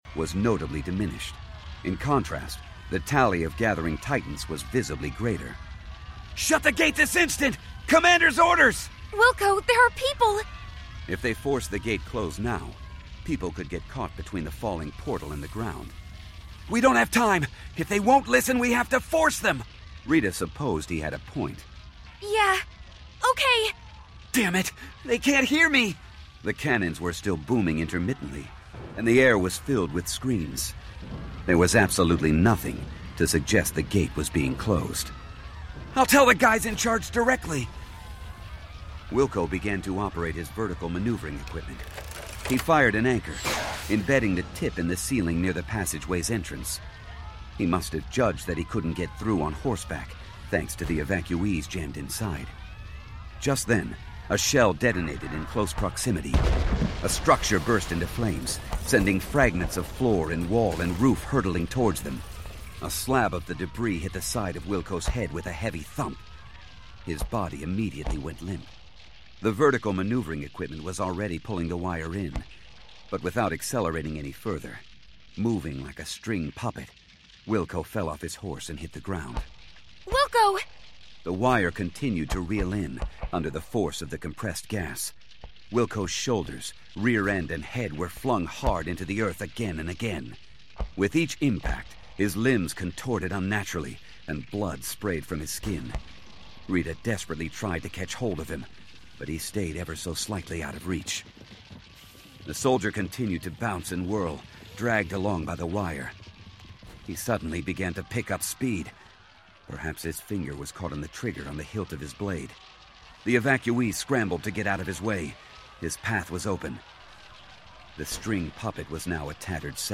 Book 1 Unabridged Audiobook Categories